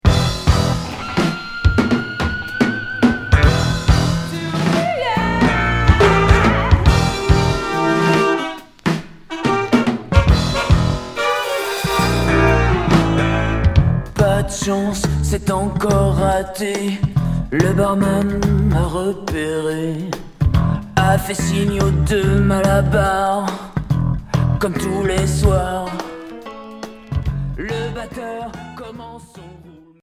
Rock new wave expérimental